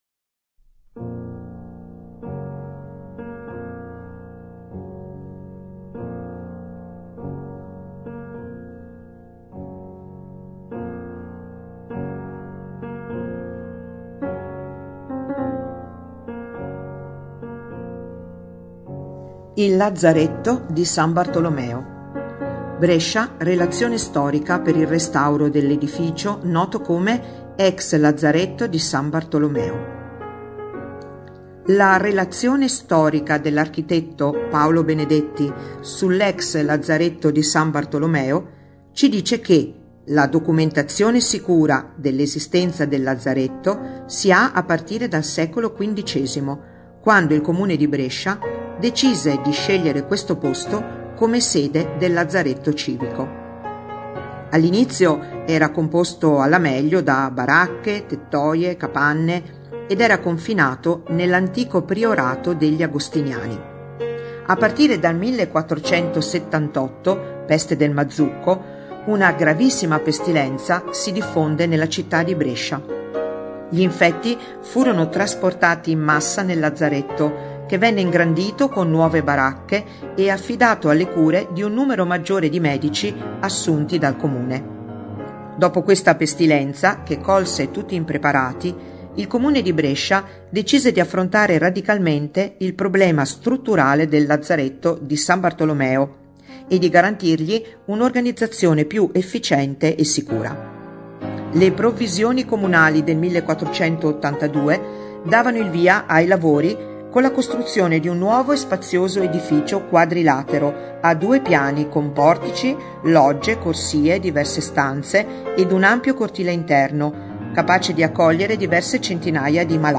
Musica: Chopin- Sonata n. 2 op. 35, Marcia Funebre